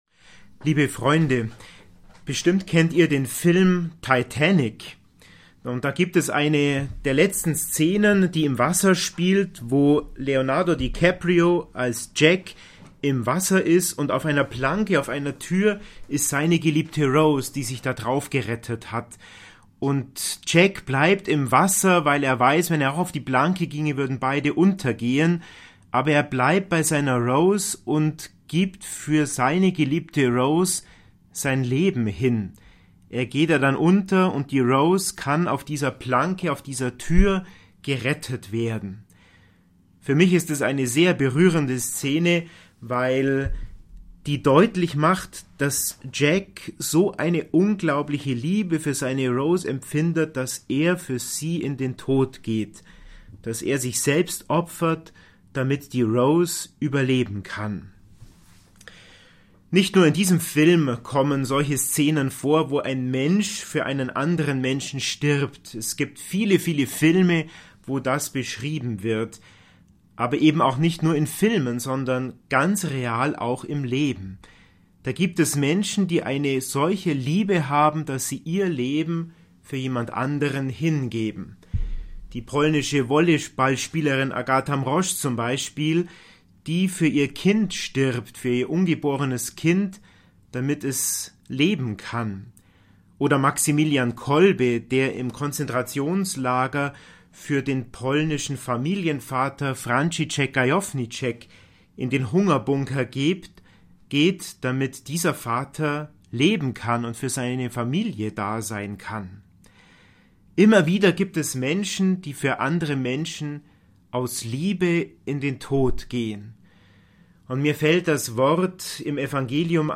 Die Karfreitagspredigt